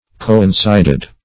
Coincided - definition of Coincided - synonyms, pronunciation, spelling from Free Dictionary
Coincide \Co`in*cide"\, v. i. [imp. & p. p. Coincided; p. pr.